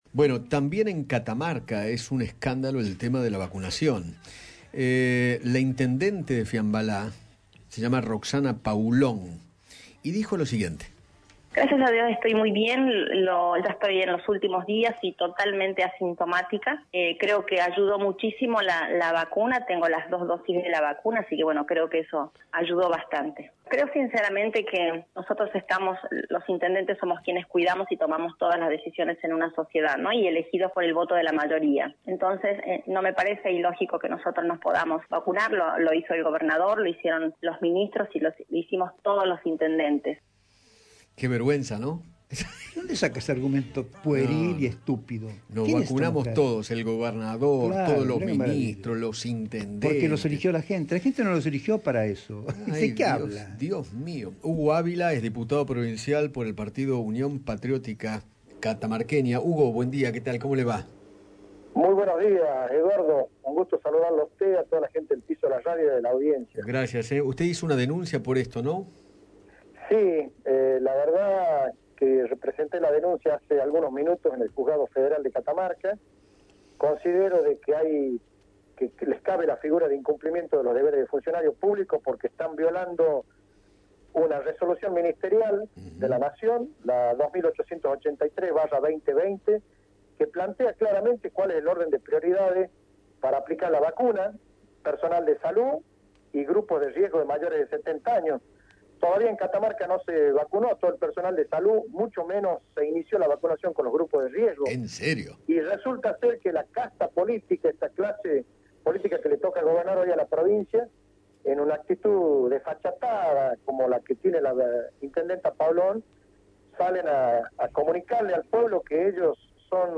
Hugo Ávila, diputado provincial por Catamarca, dialogó con Eduardo Feinmann acerca de la denuncia que realizó contra Roxana Paulon, ministra de Salud de aquella provincia, quien sostuvo que “no me parece ilógico que nosotros nos podamos vacunar. Lo hicimos todos: intendentes, gobernadores y ministros”.